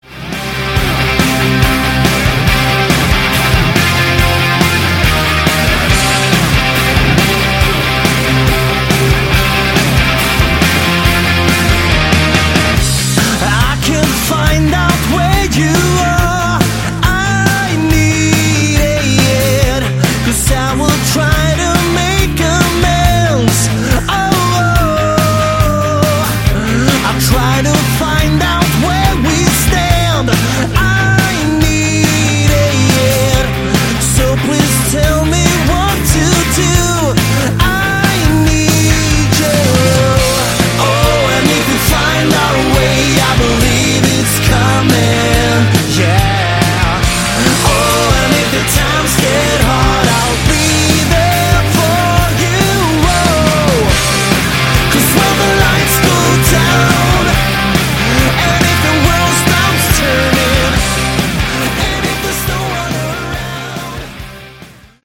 Category: Melodic Rock
Lead Vocals, Guitar
Drums, Percussion
Bass